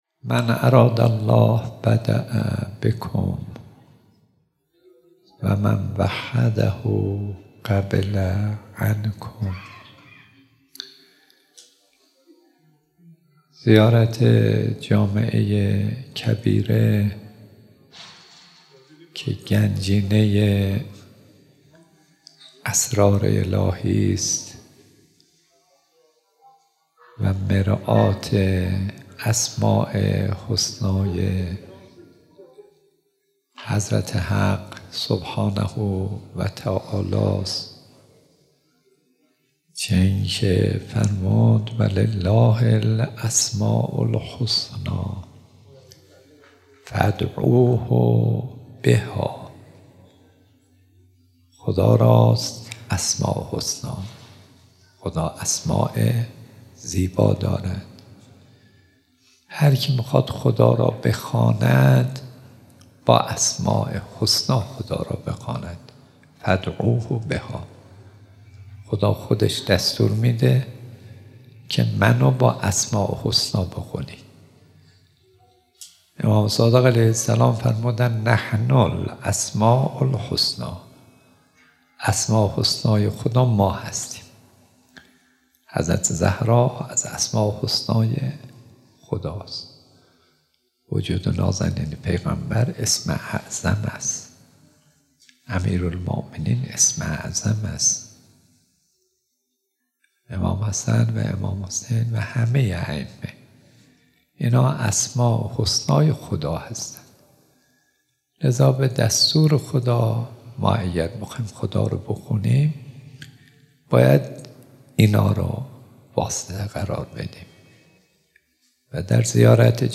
بیانات دلنشین و زیبای خطیب دانشمند استاد صدیقی که دهه دوم صفر ۹۶ به مدت دو شب بمناسبت بدرقه زائرین اربعین حسینی درحسینیه حضرت آیت الله العظمی مرعشی نجفی (ره) ایراد گردیده است جهت استفاده شما بزرگواران از طریق لینکهای زیر قابل دریافت است :
sokhanrani.mp3